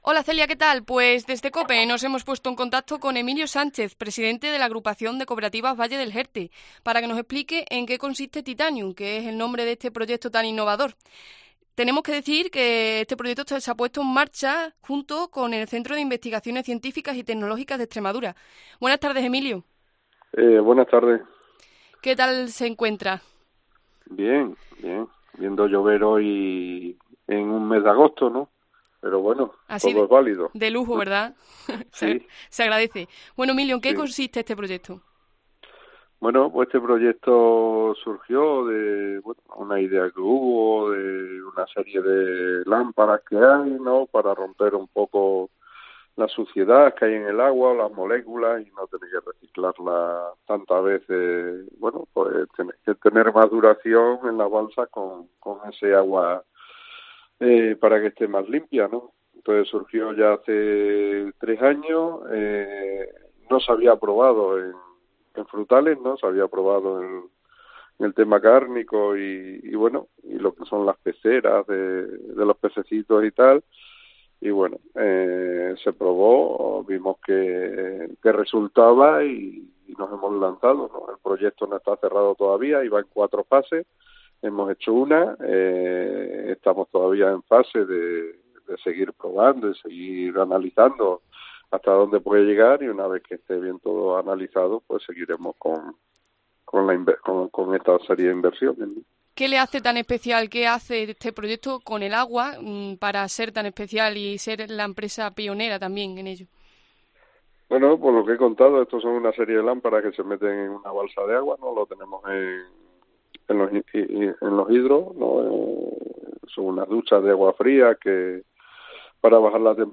ha pasado hoy por ACDR para hablarnos de 'Titanium'